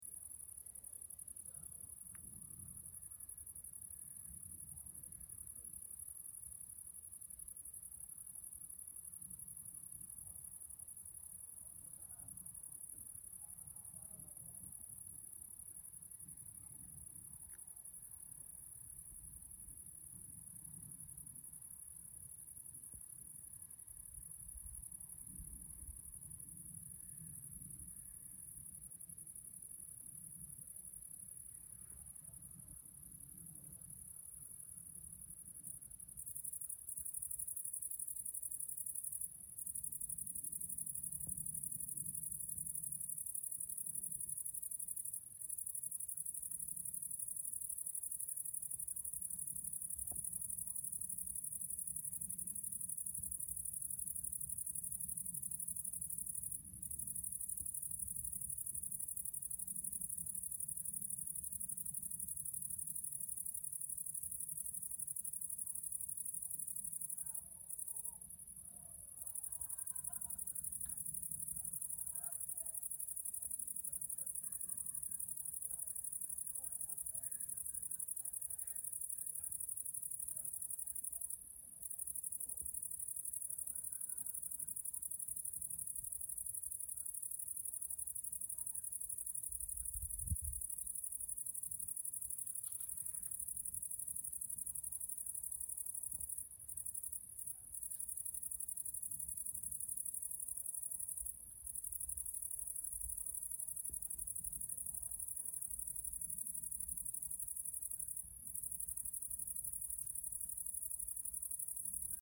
Стрекотание сверчков ночью и вечером на фоне природы в mp3
13. Звук ночных сверчков в поле
nochnye-sverchki-v-pole.mp3